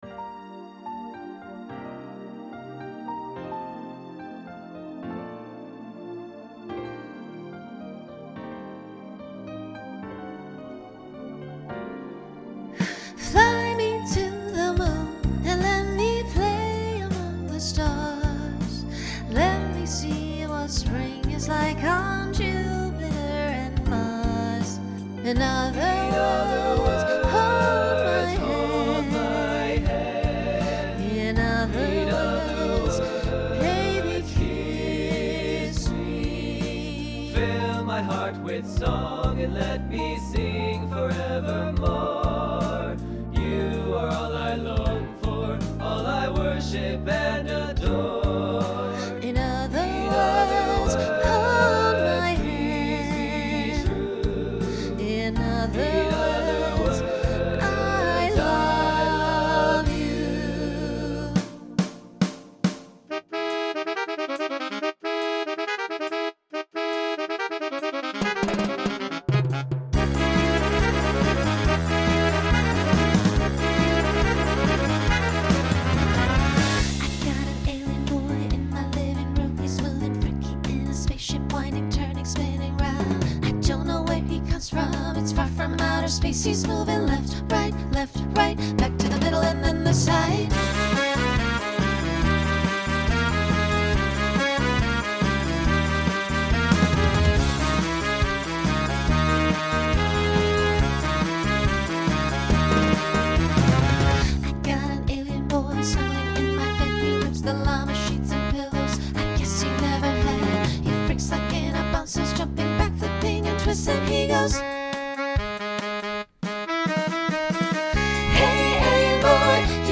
Genre Pop/Dance
Voicing Mixed